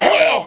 pain1.wav